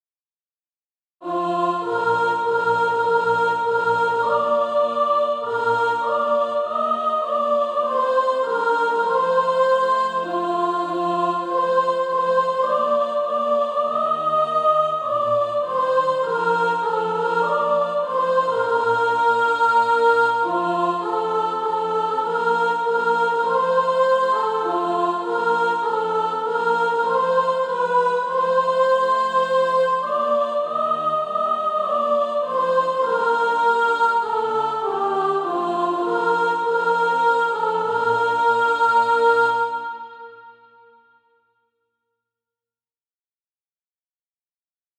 Soprano Track.